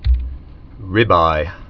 (rĭbī)